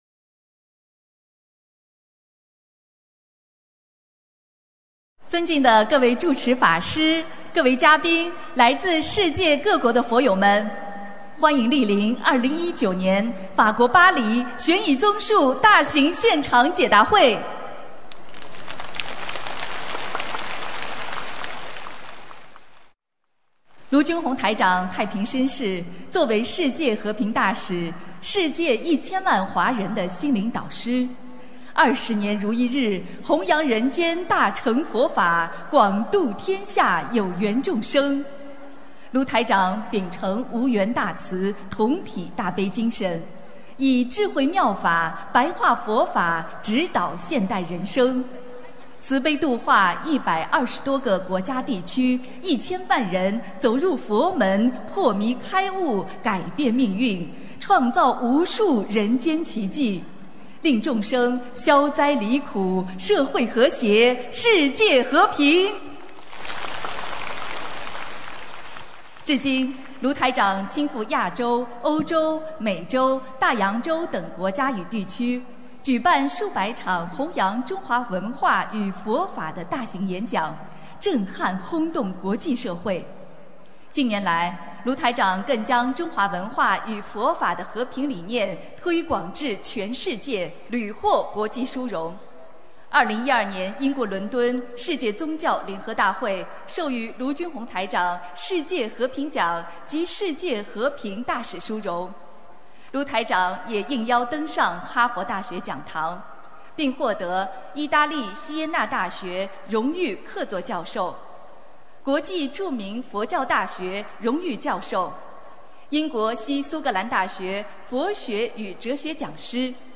2019年9月8日法国大法会（视音文图） - 2019-2020年 - 心如菩提 - Powered by Discuz!